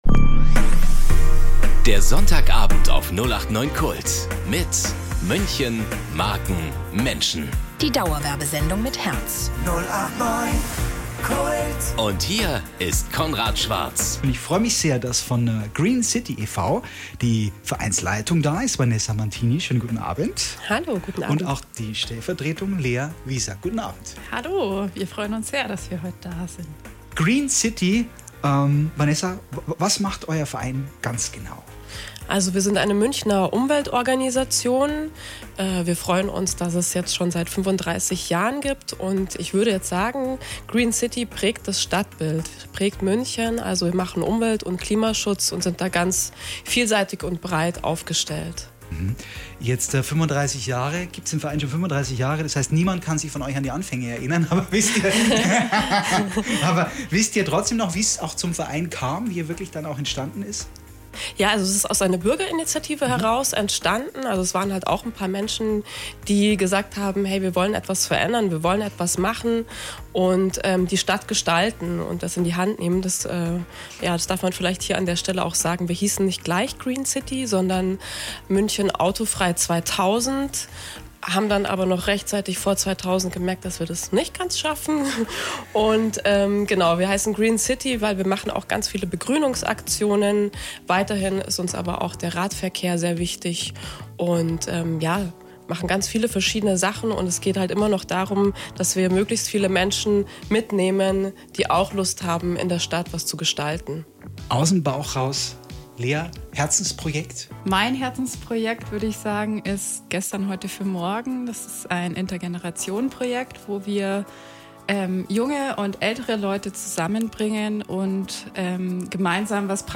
Hier gibt’s das komplette Interview: